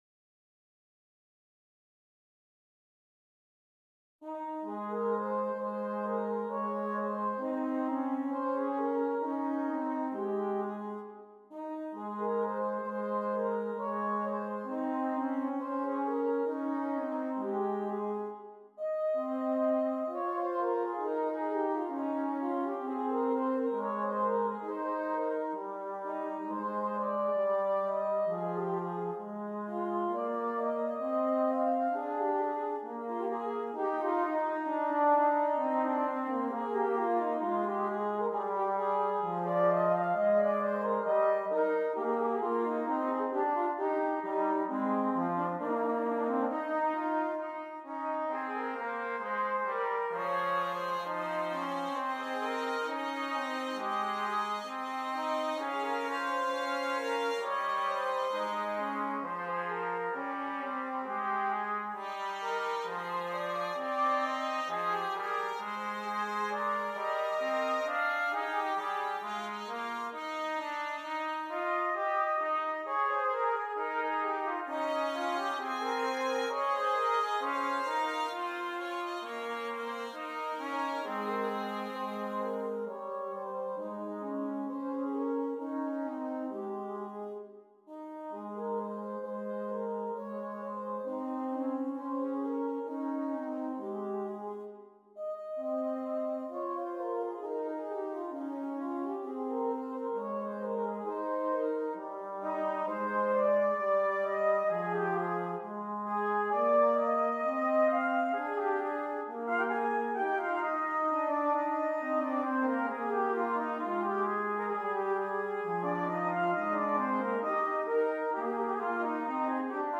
Brass Band
6 Trumpets